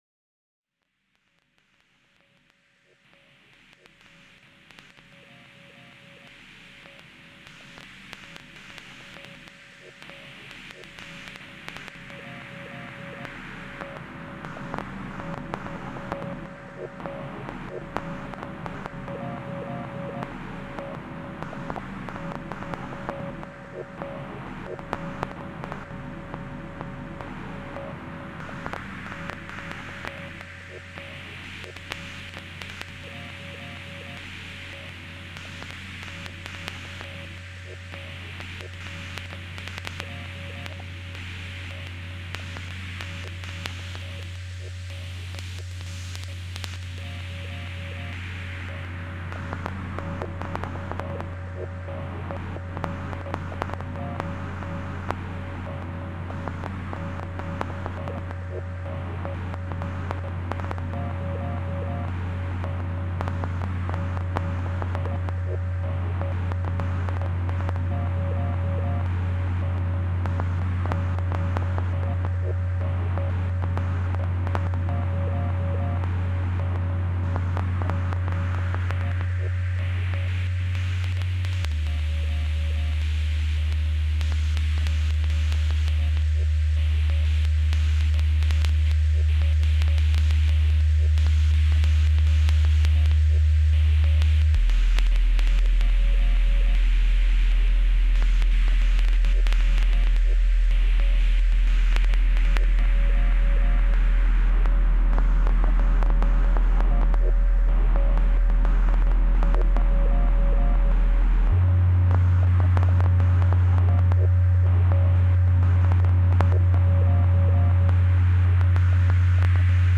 microsonic sound adventure